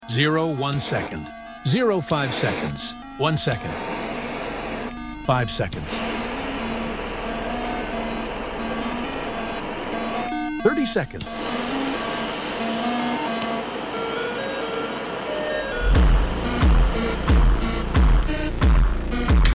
Powerful Lighter vs Tangerine Slices sound effects free download